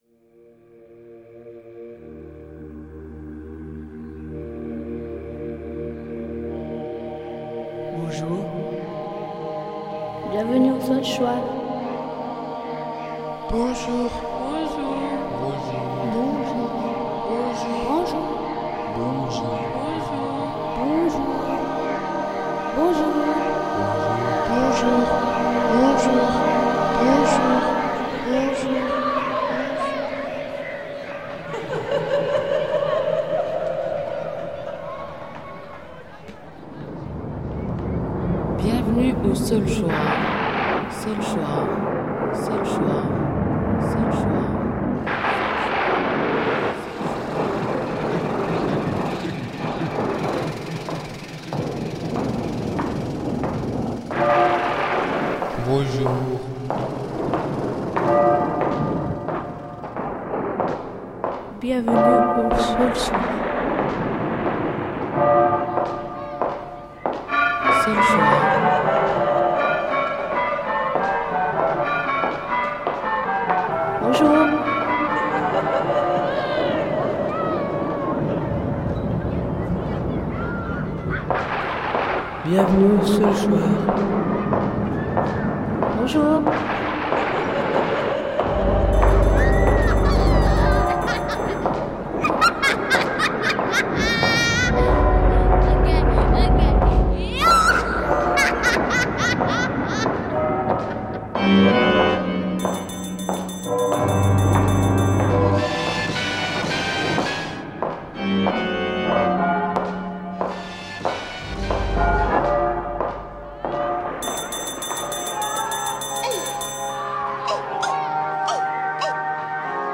Réalisé par les élèves à l’aide de leurs professeurs, un fond musical berçait l’intérieur du cube au rythme des mouvements de ses mobiles scintillants…
Ci-dessous, vous pouvez encore écouter ou réécouter cette ambiance et tenter de vous replonger dans cet univers hors du temps…